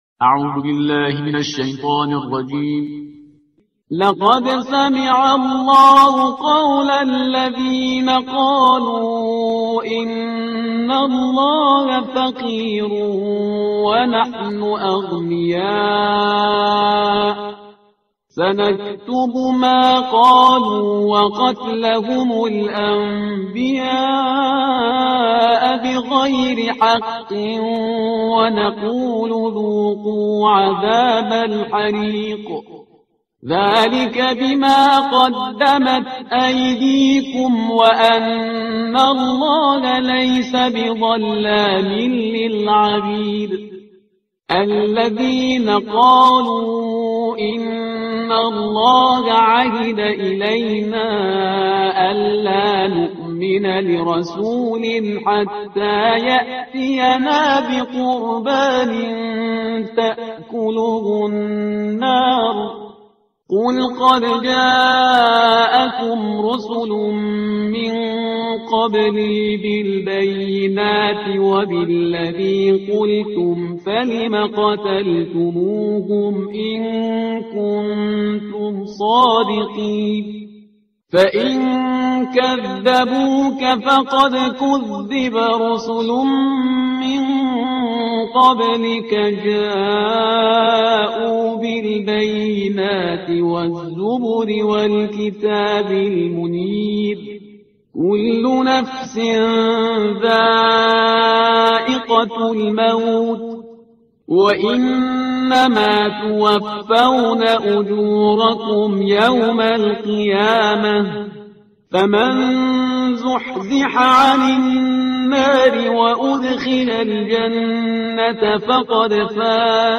ترتیل صفحه 74 قرآن